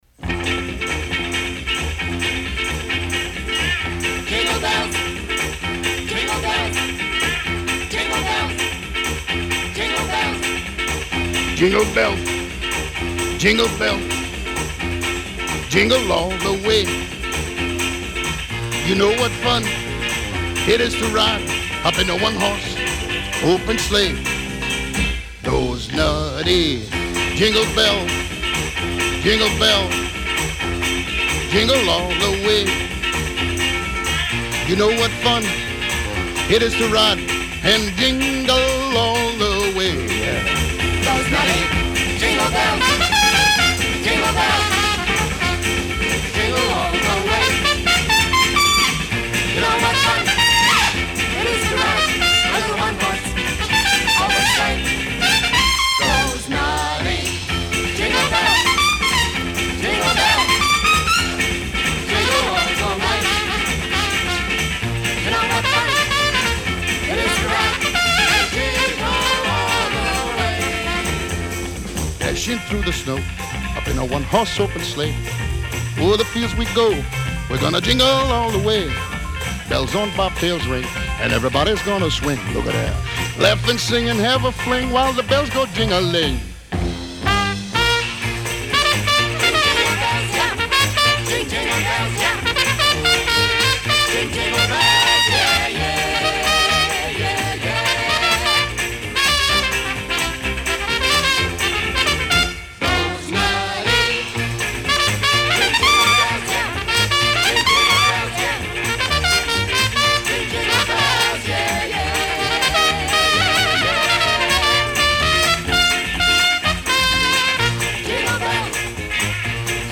Side two is typical holiday schmaltz